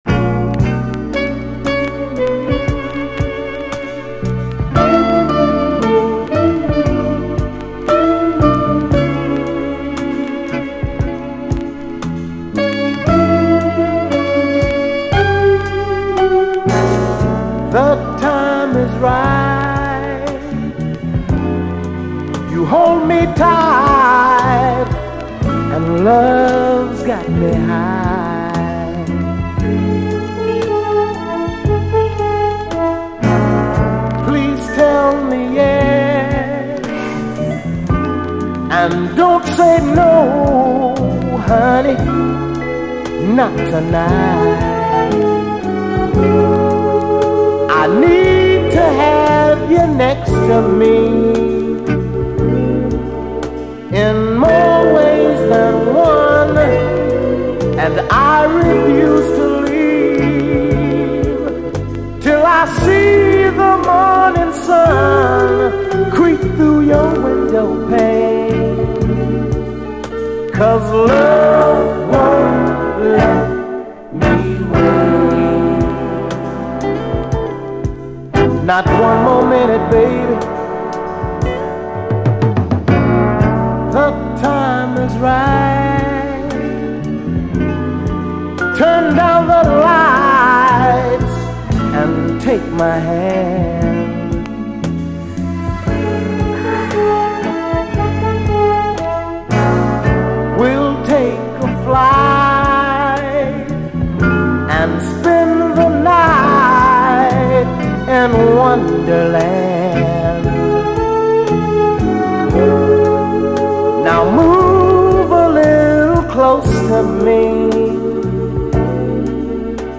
SOUL, 70's～ SOUL, 7INCH
UKラヴァーズ〜レゲエ方面でもしばしばカヴァーされる、70'Sスウィート・ソウル・クラシック！